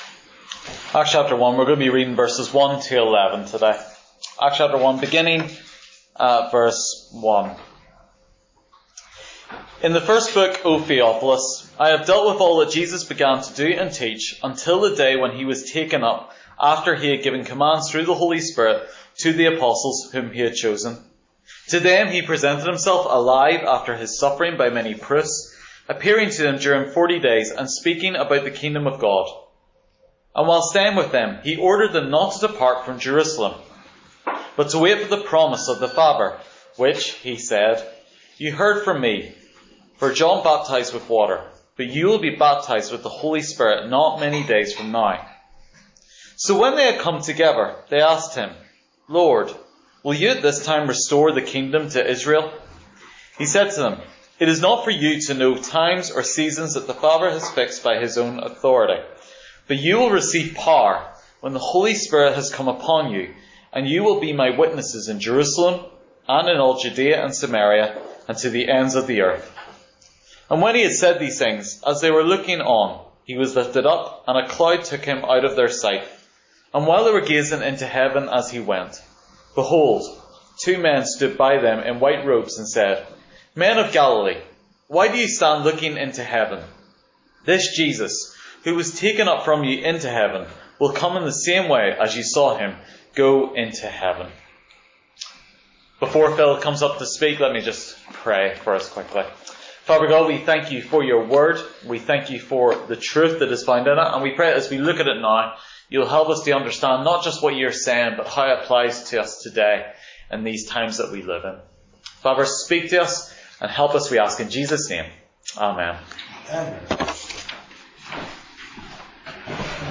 Guest Interviews: Can self help really save?